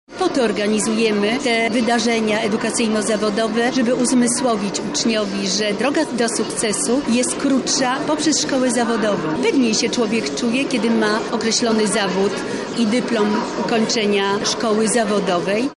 O szczgółach mówi lubelski wicekurator oświaty Anna Dudek-Janiszewska